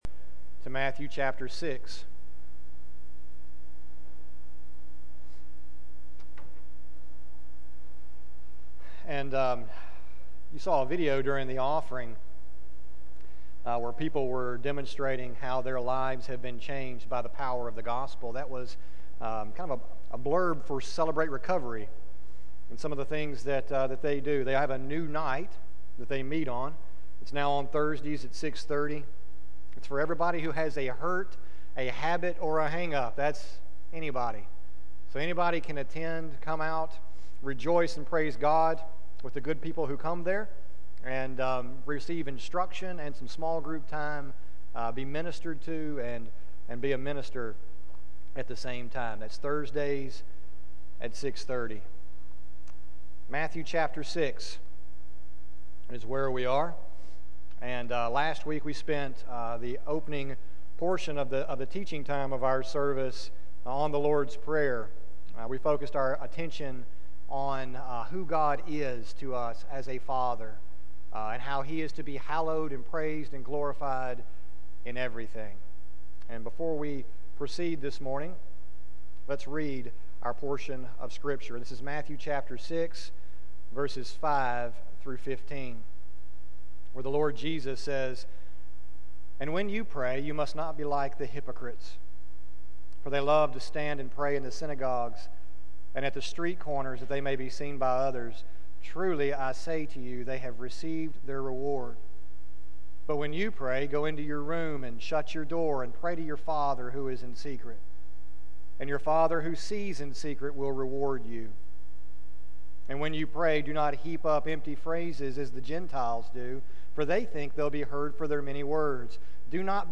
sermon080617a.mp3